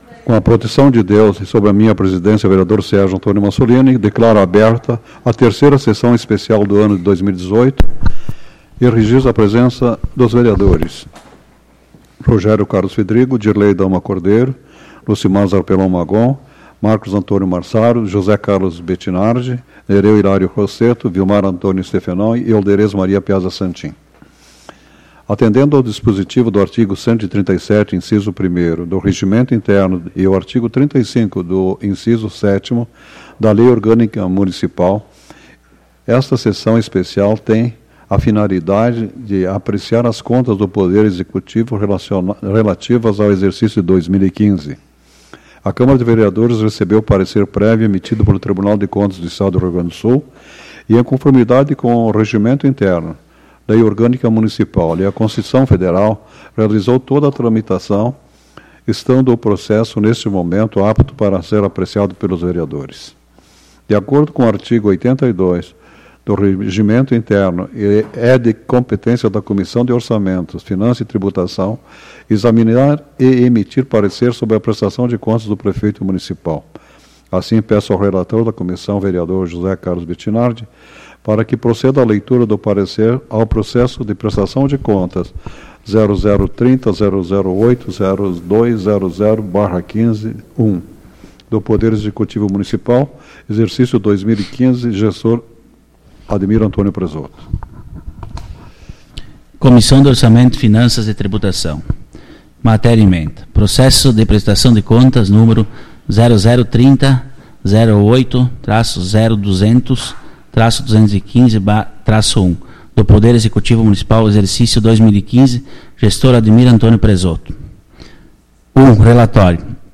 Tipo de Sessão: Especial